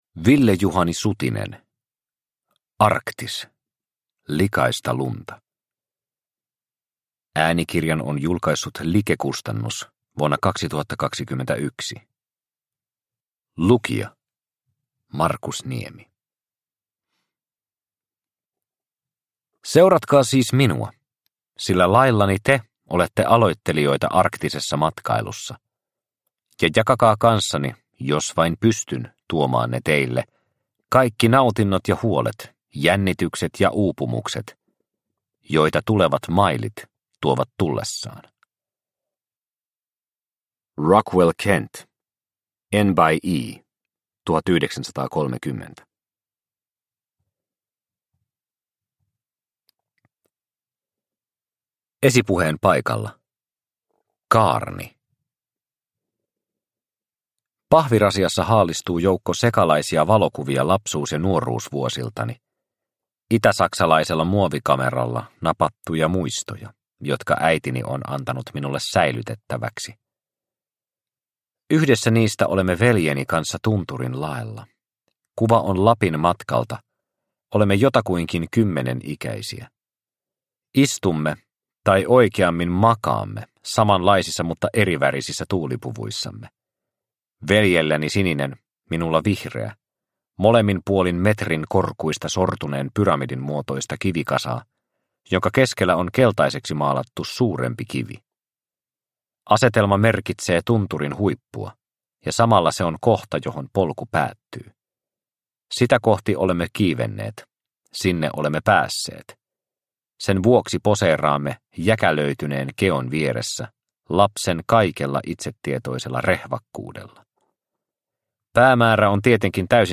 Arktis – Ljudbok – Laddas ner